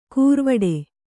♪ kūrvaḍe